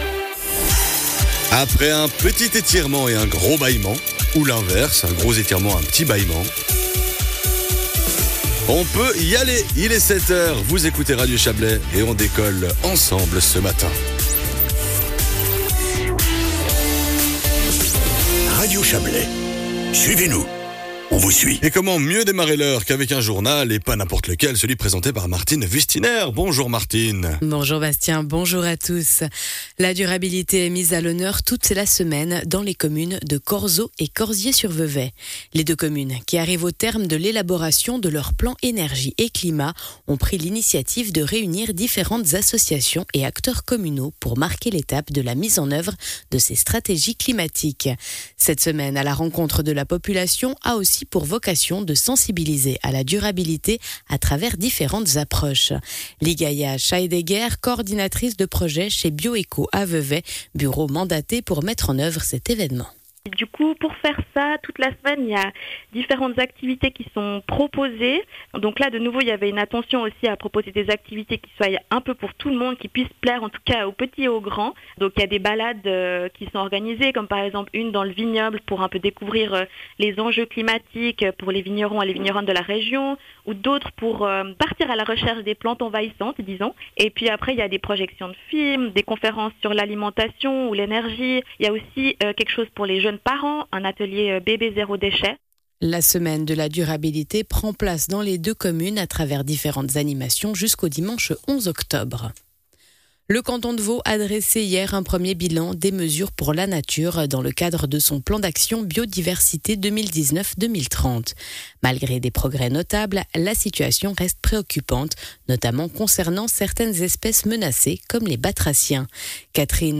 Le journal de 7h00 du 07.10.2025